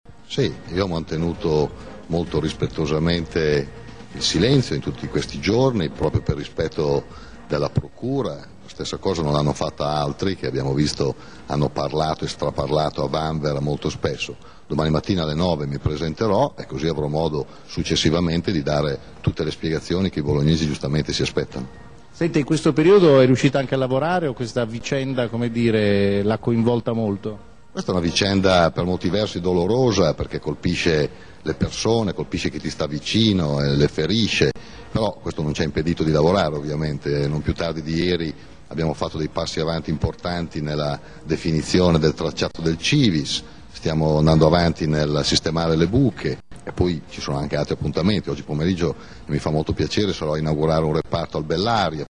Il sindaco oggi ha intanto fatto un appello ai bolognesi dalle telecamere di E’-tv.